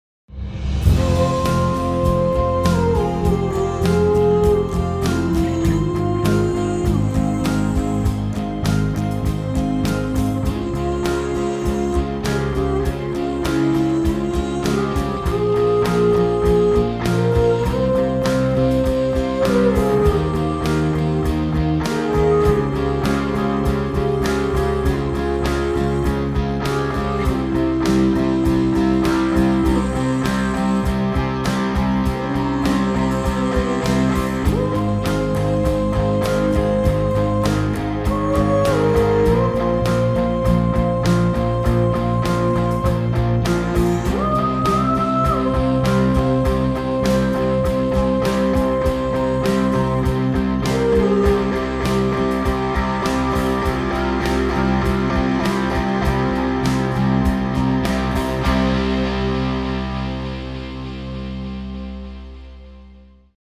newly recorded album